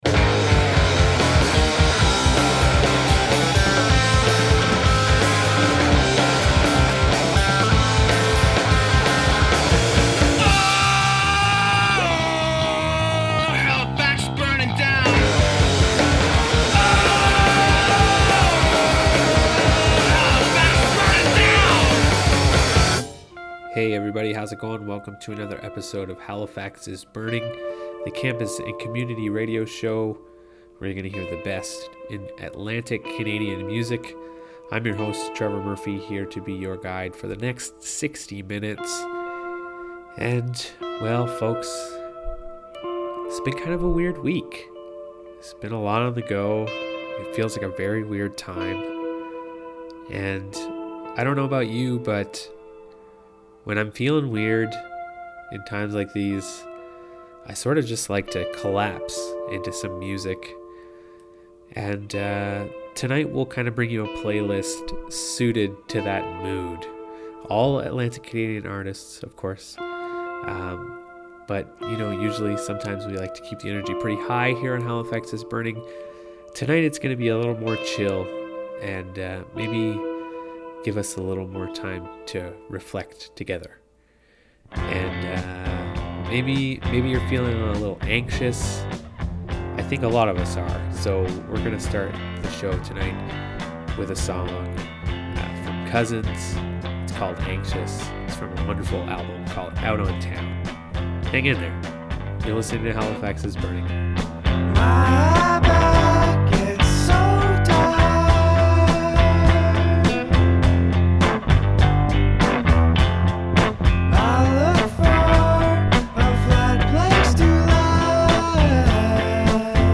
The best independent East Coast music